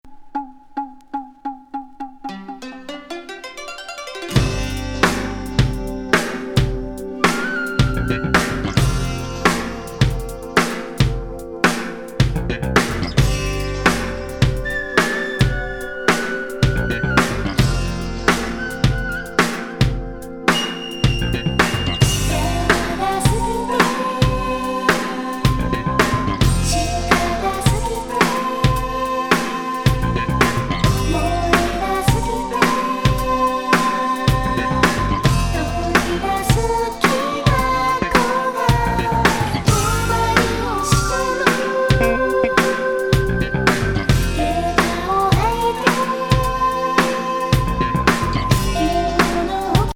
笛、琴、鼓にエキセントリック・女性ボーカル
入りのエスノ和レアリック・フュージョン・グルーブ